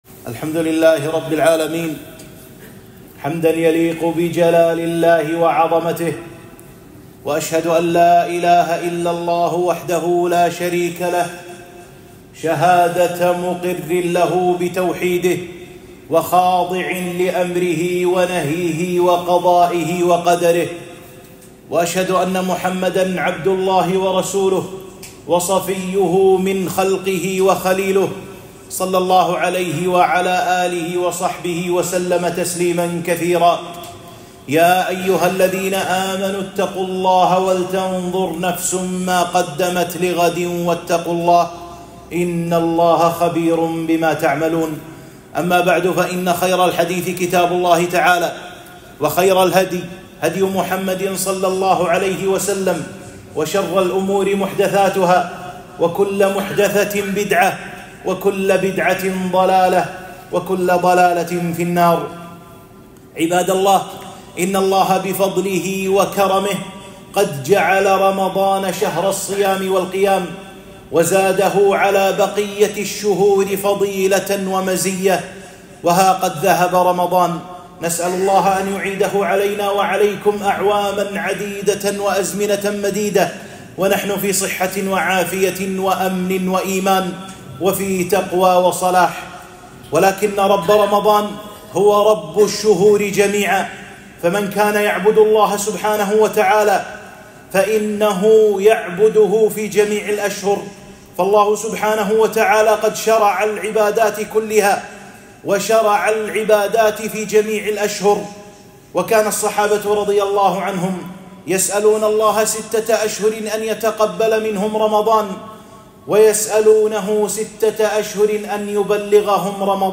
خطبة - المداومة على الطاعات